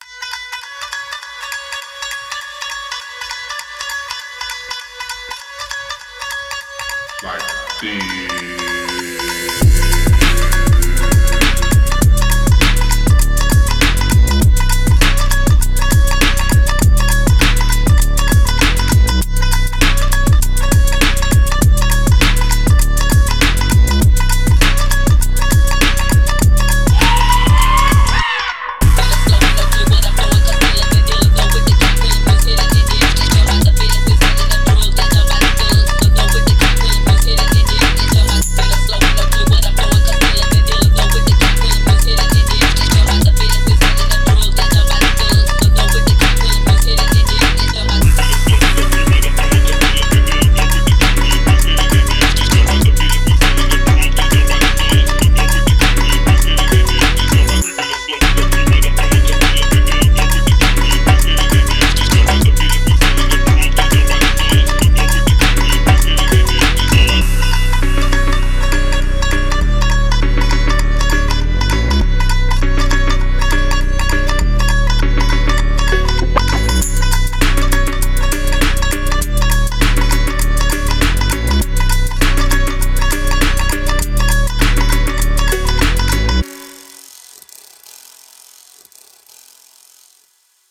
Phonk Размер файла